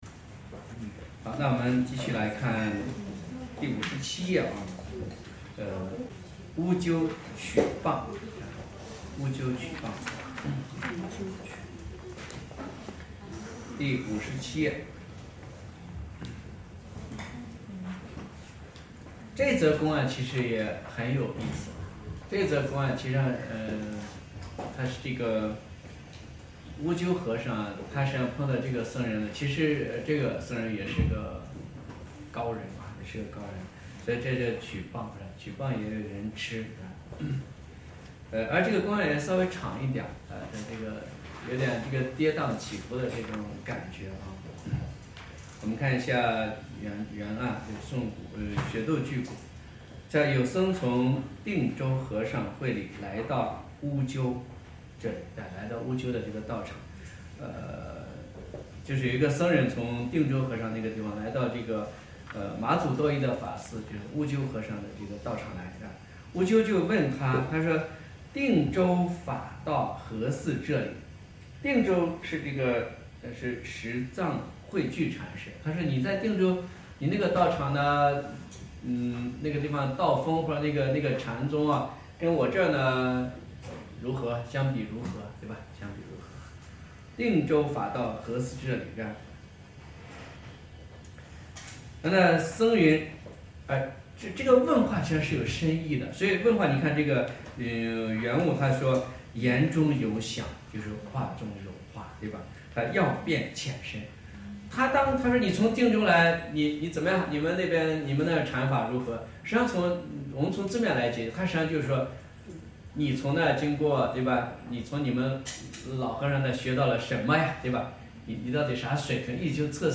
讲于青城山